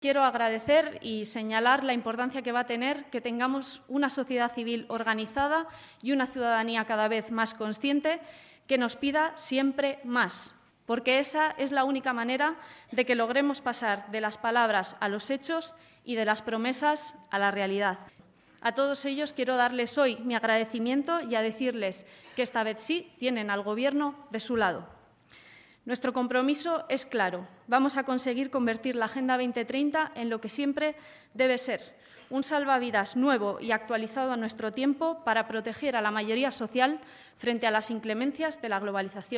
expresó por su parte formato MP3 audio(0,60 MB) su agradecimiento a los colectivos y movimientos ciudadanos, “los que más han hecho durante estos años por que se cumplan esos objetivos”, y se comprometió a que, en la lucha por alcanzarlos, “van a tener al Gobierno de su lado”.